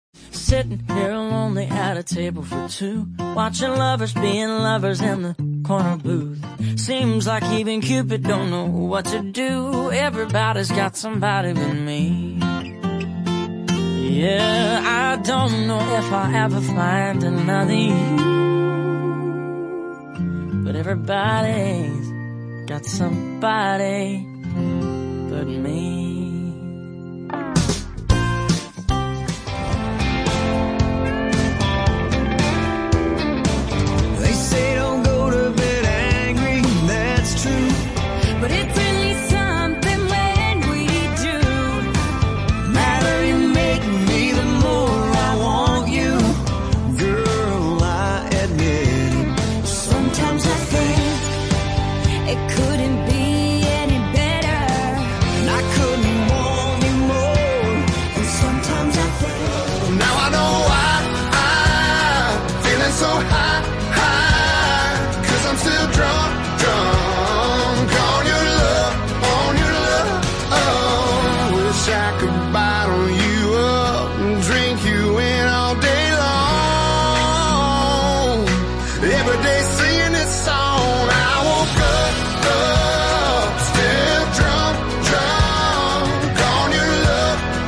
Country Hits from Popular Artists